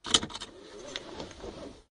unbuckle.ogg